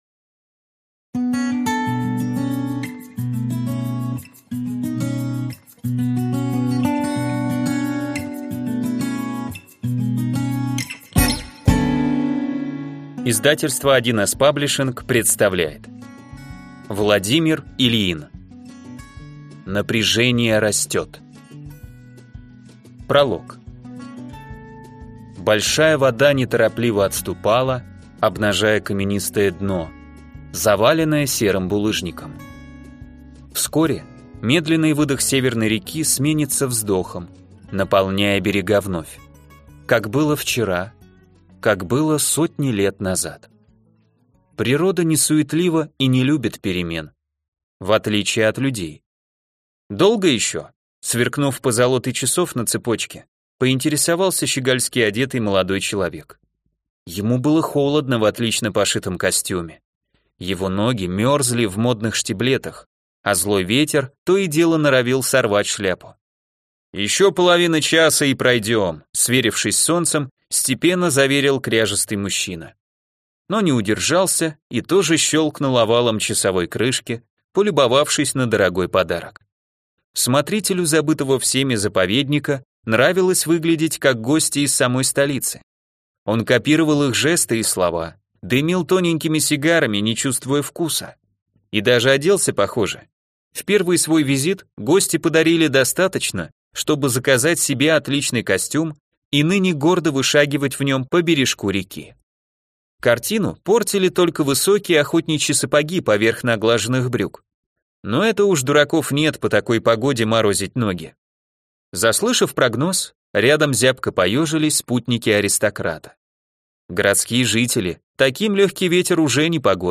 Аудиокнига Напряжение растет - купить, скачать и слушать онлайн | КнигоПоиск